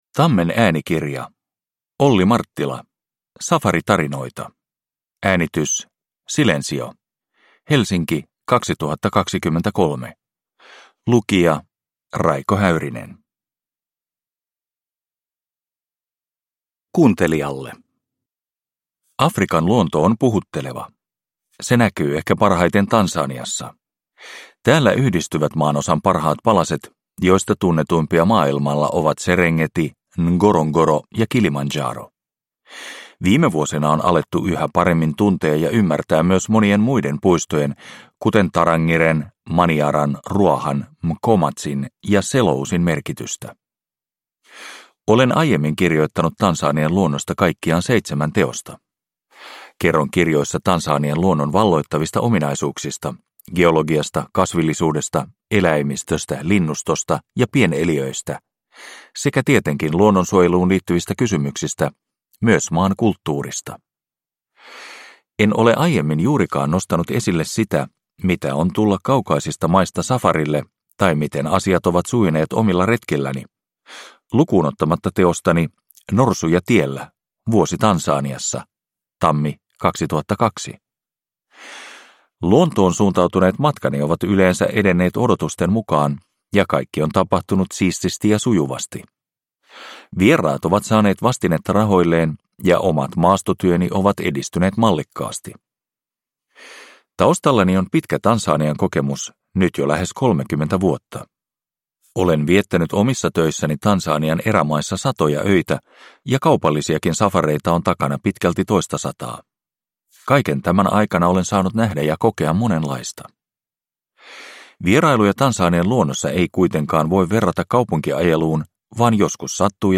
Safaritarinoita – Ljudbok – Laddas ner